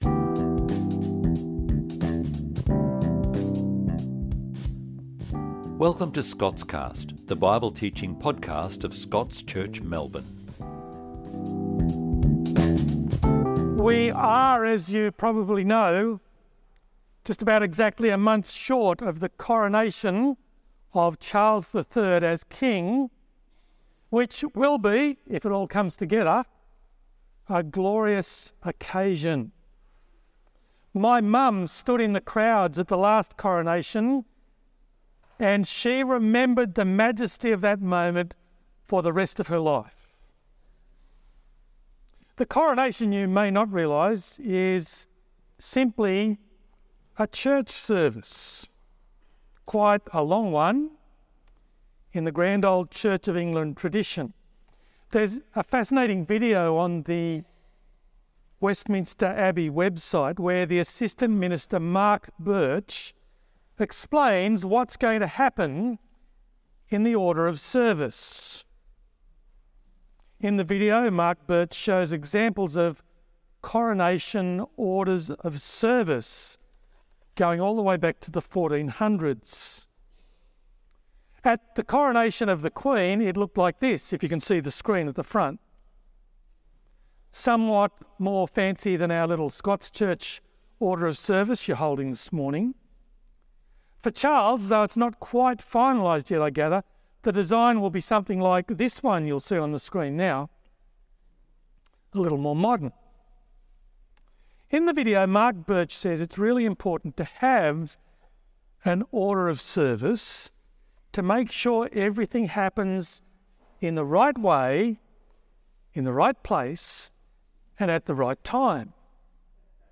Sermon Only Audio